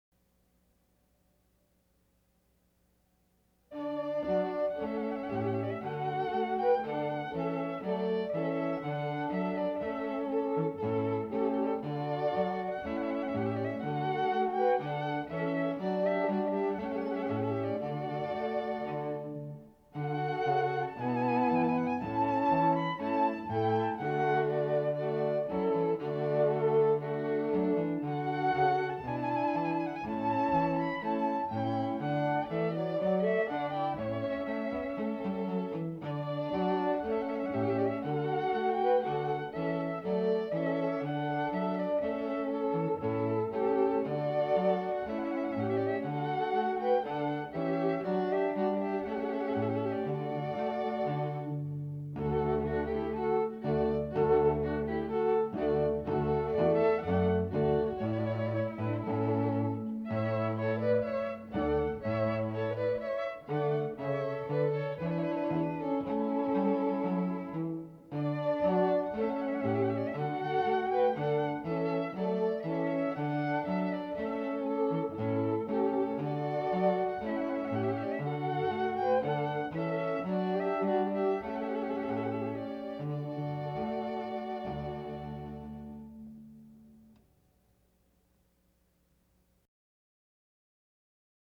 Wedding Planner, wedding music, string quartet music